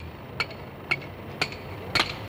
Chisel Sound On Cement